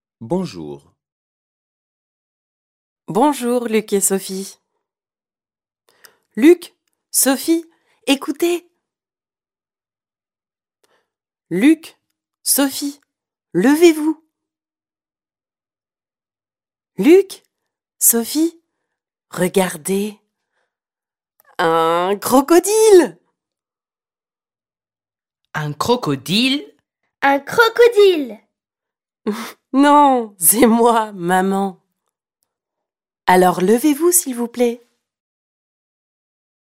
To help non-specialist teachers model good pronunciation and provide valuable practice in listening skills, the book comes with free audio downloads and English translations of the stories.